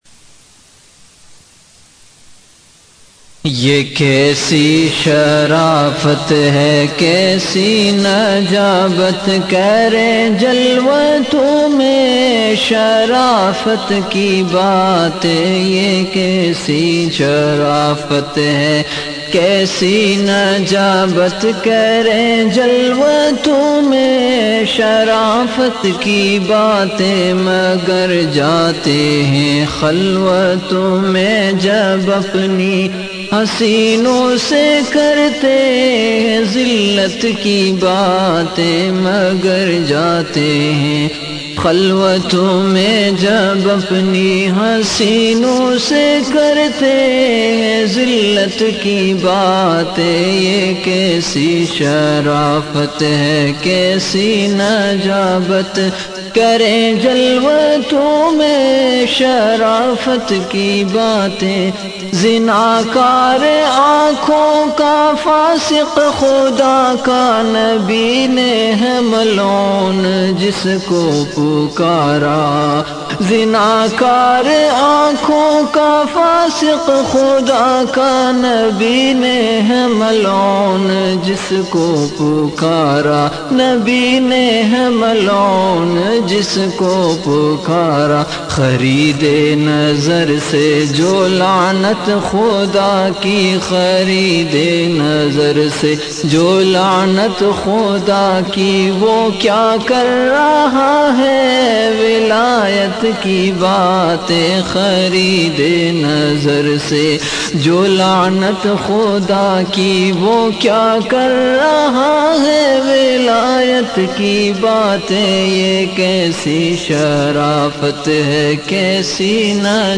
Ye Kaisi Sharafat Hai(Studio Rec)
CategoryAshaar
Event / TimeAfter Isha Prayer
Ye Kaisi Sharafat Hai(Studio Rec).mp3